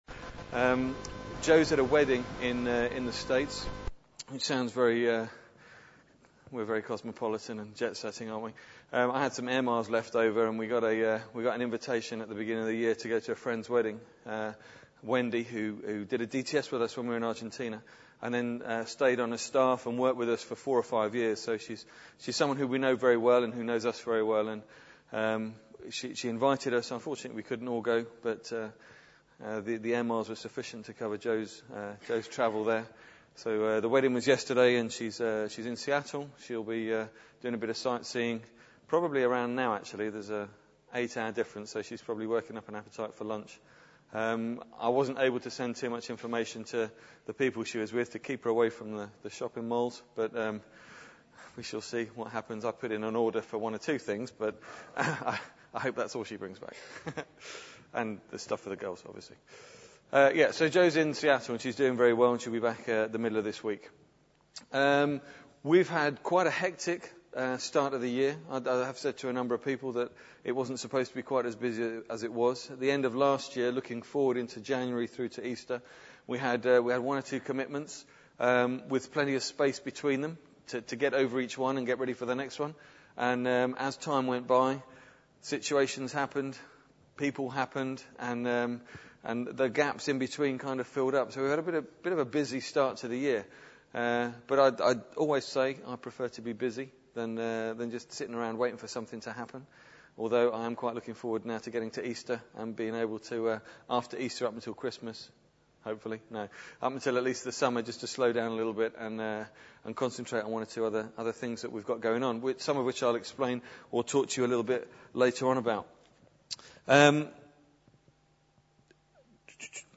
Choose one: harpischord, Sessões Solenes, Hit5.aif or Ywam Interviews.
Ywam Interviews